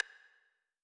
Click_JJ.wav